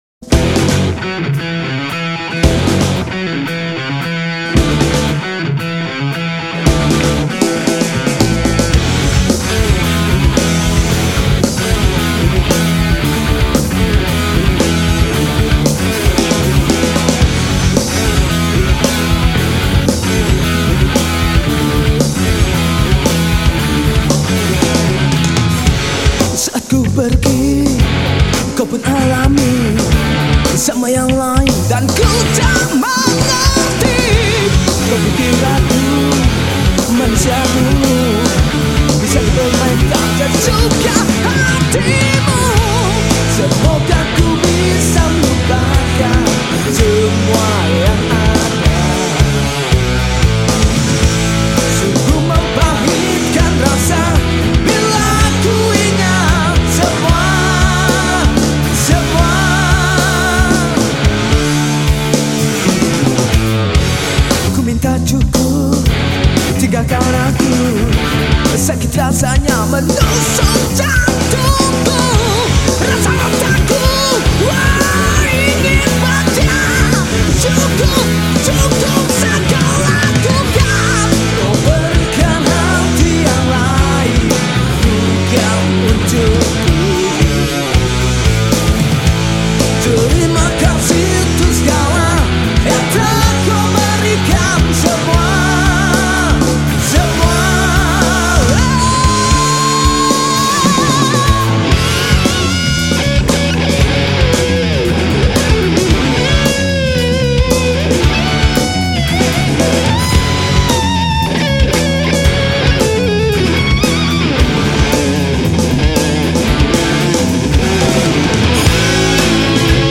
Рок
Жанр: Жанры / Рок